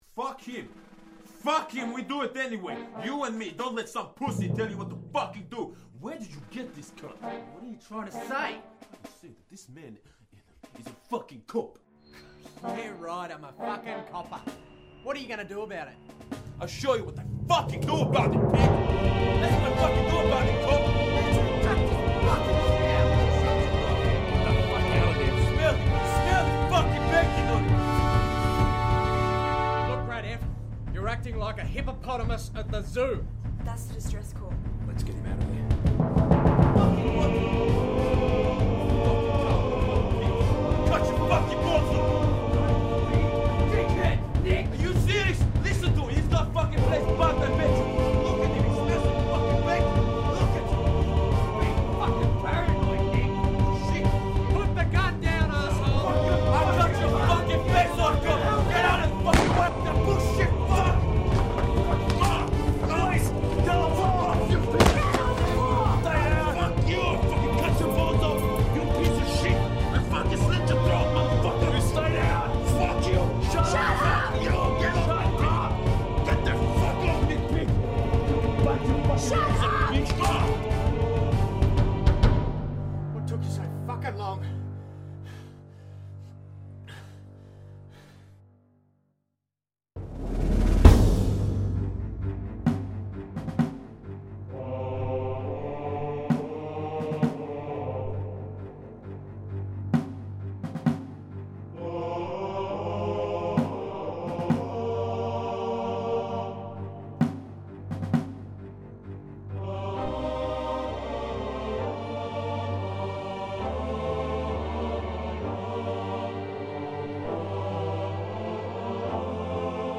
film score